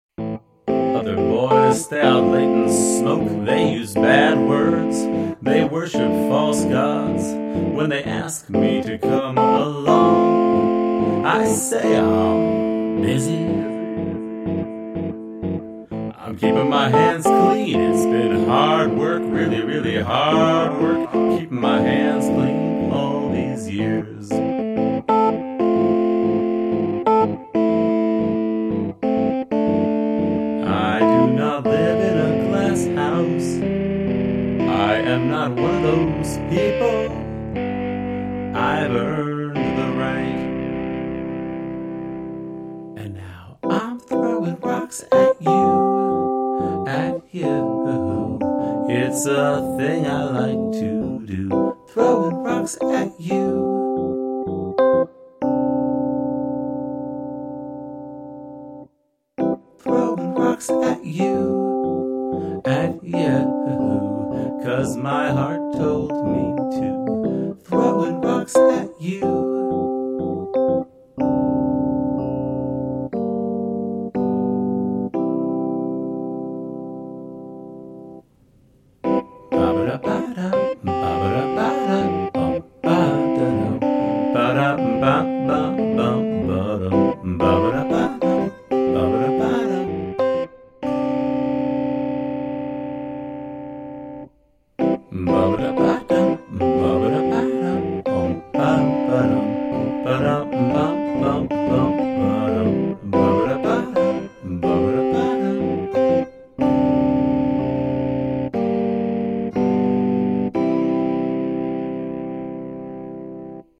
A Thing I Like to Do, the musical version of a post from a few weeks ago, now with vocals, keyboards, and a light dusting of guitar.
Love the sinister throb of the keys in this one.